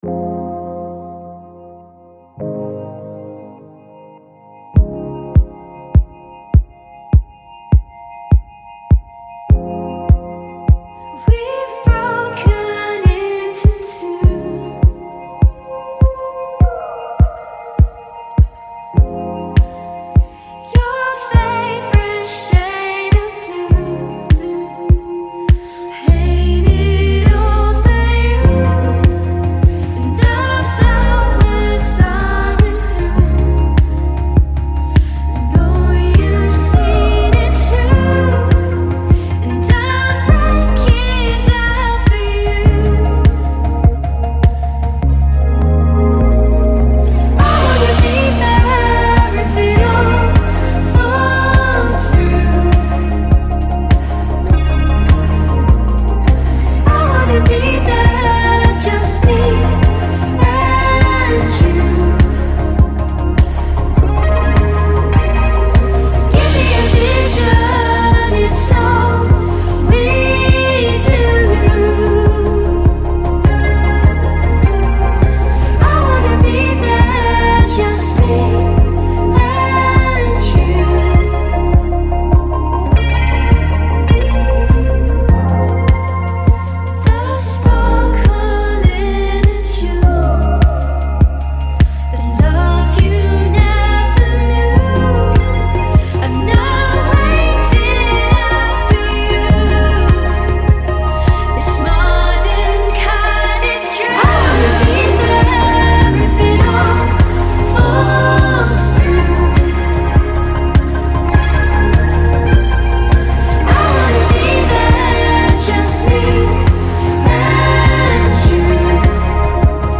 East Anglian [UK] duo
shimmering new electro pop single
carefully crafted ambient pop
Marrying guitar lines with liquid synths, the […]